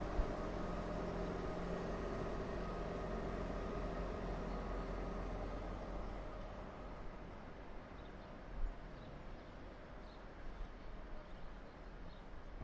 萨格勒布 " 萨格勒布Deisel列车
描述：缩放H1萨格勒布火车站早上通勤列车
Tag: 列车 铁路